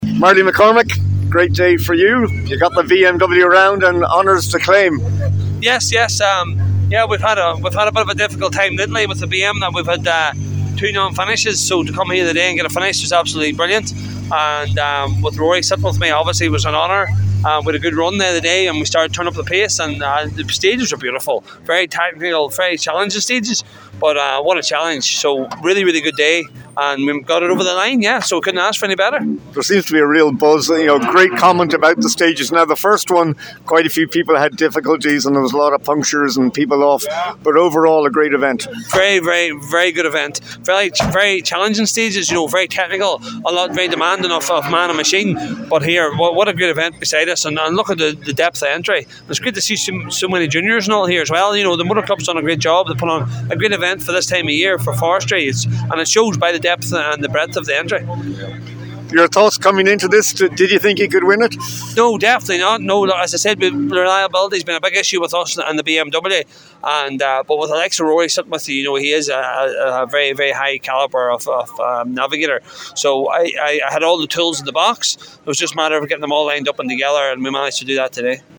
Donegal Forestry Rally – Reaction from the finish line